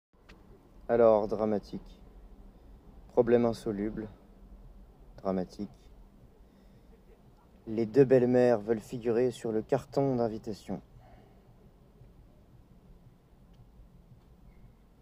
Bandes-son